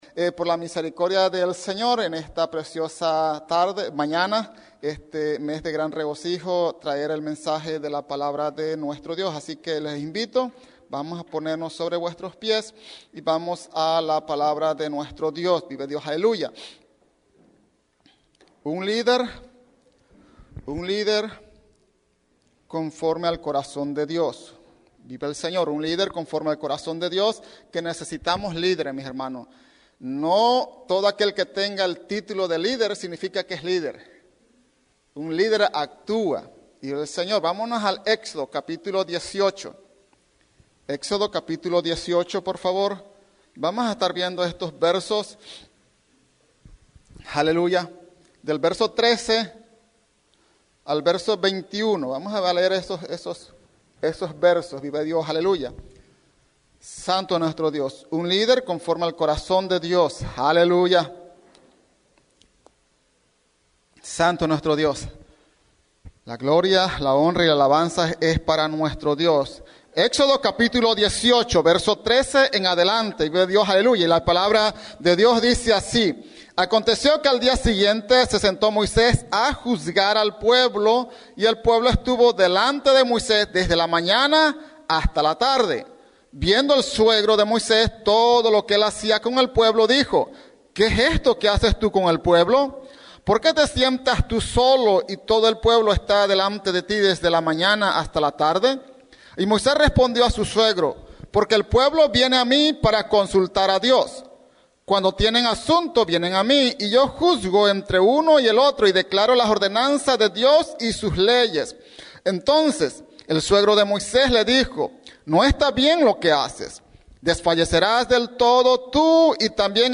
Mensaje
en la Iglesia Misión Evngélica en Norristown, PA